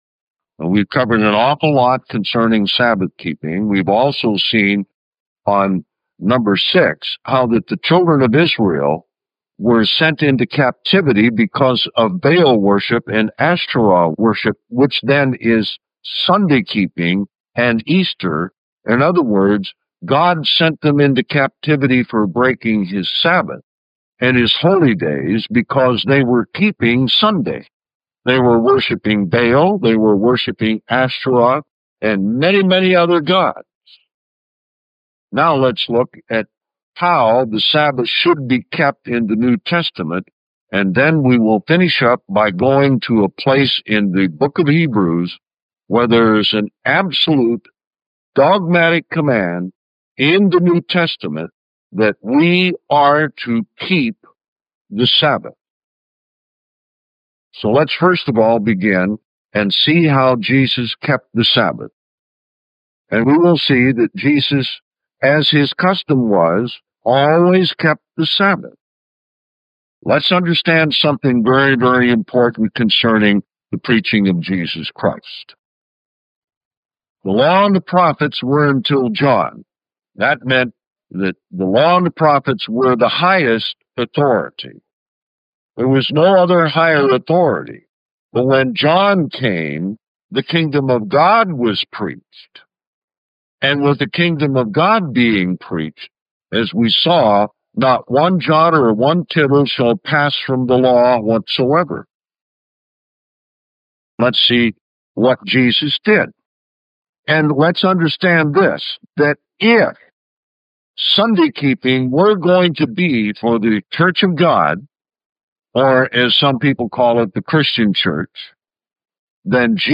This tape is Holy Sabbath number eight.